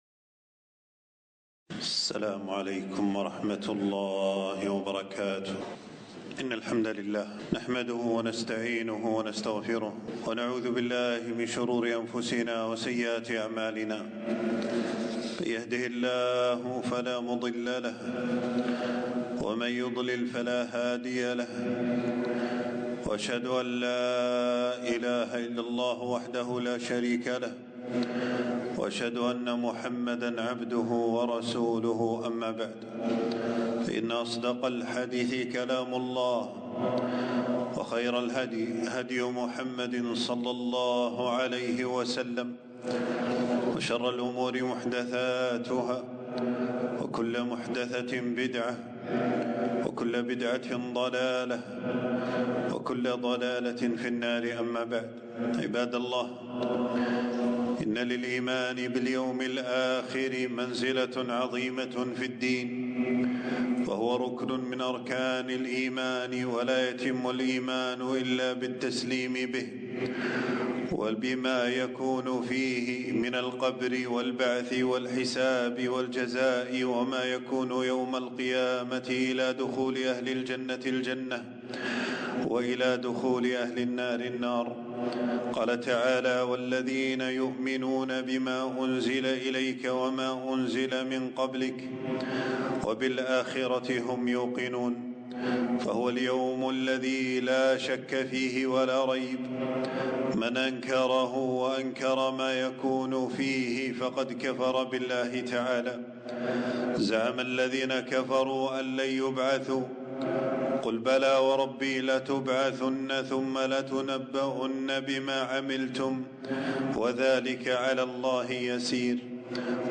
خطبة - ونضع الموازين القسط ليوم القيامة